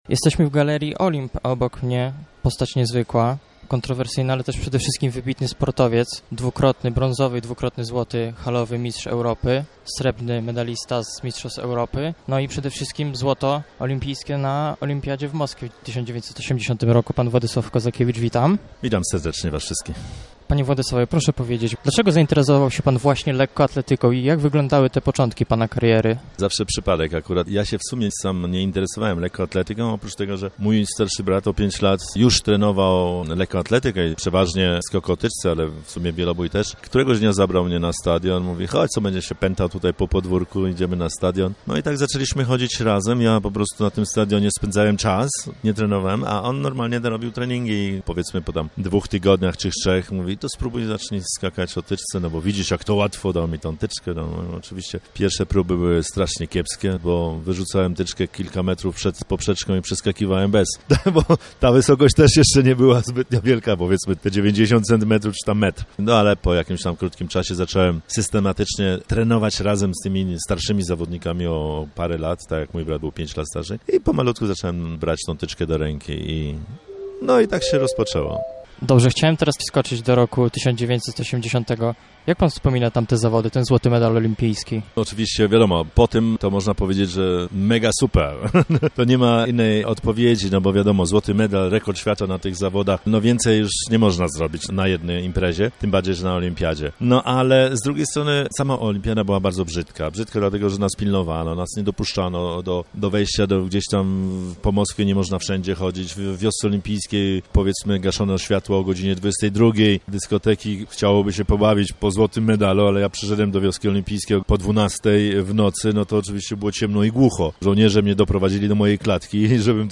Autor najsłynniejszego gestu w historii polskiego sportu. Władysław Kozakiewicz spotkał się z mieszkańcami w lubelskiej Galerii Olimp.